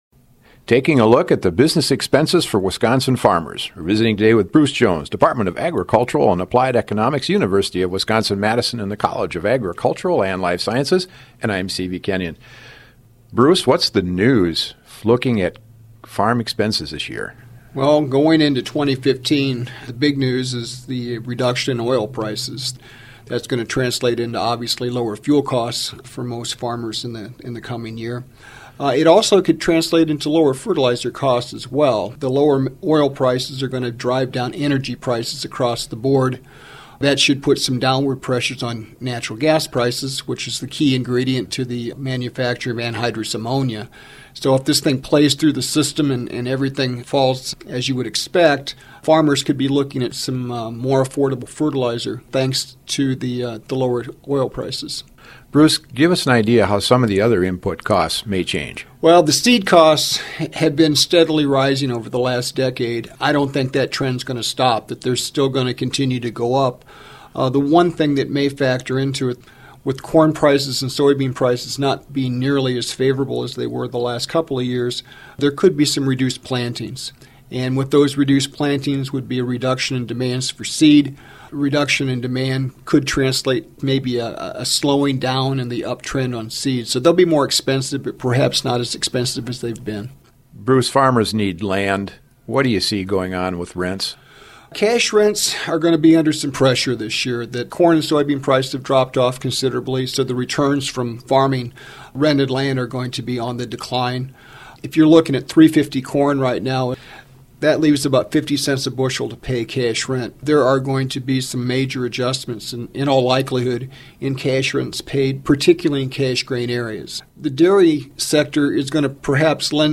Agricultural Economist